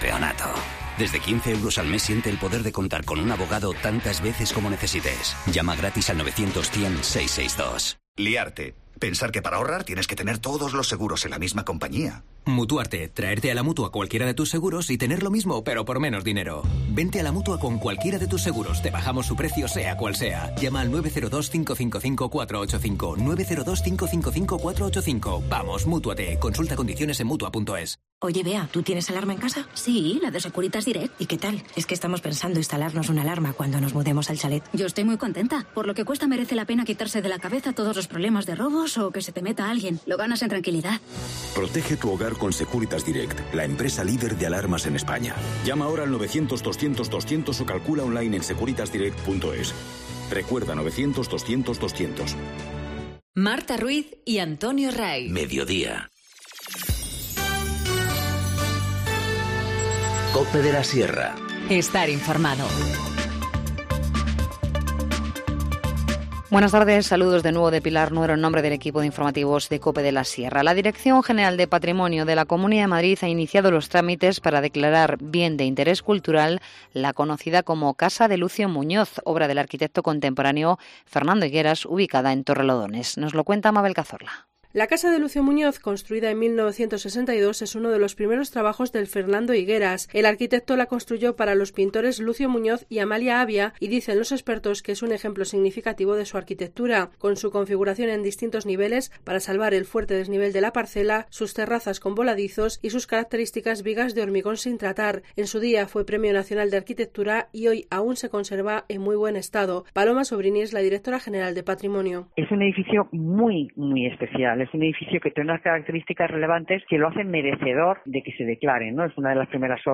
Informativo Mediodía 25 marzo 14:50h